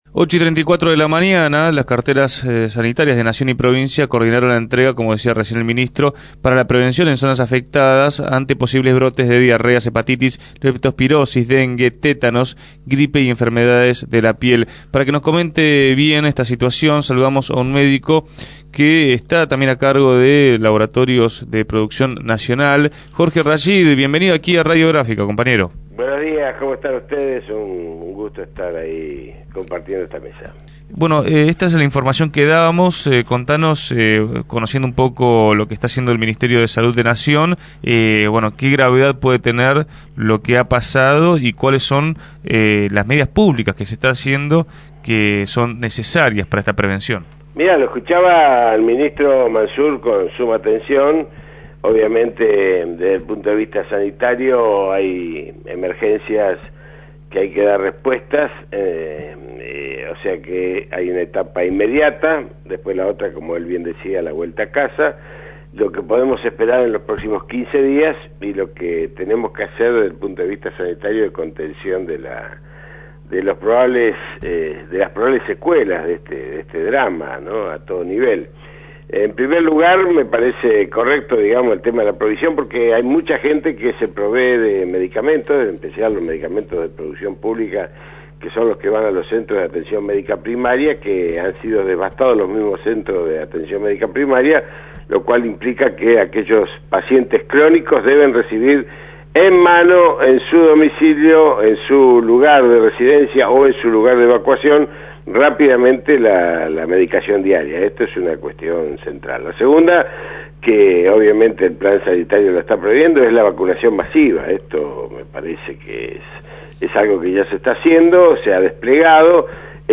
En una extensa entrevista realizada en Punto de Partida